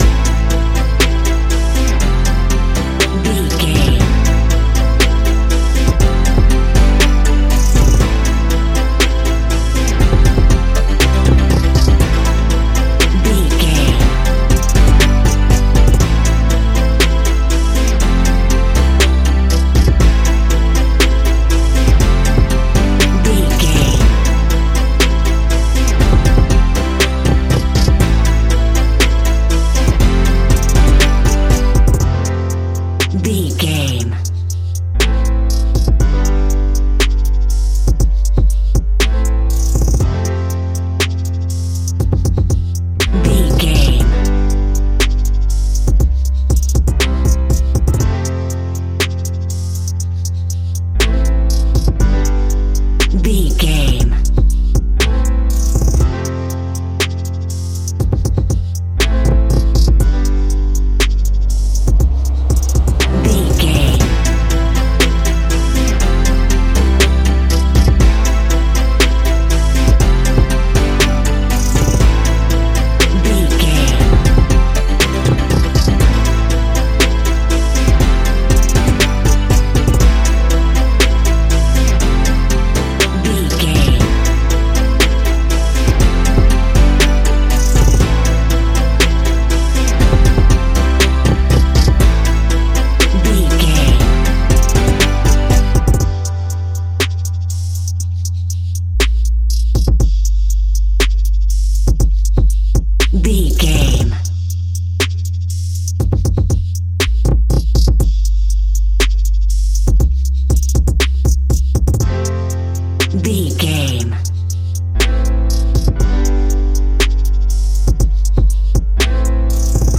Ionian/Major
ambient
electronic
chill out
downtempo
synth
pads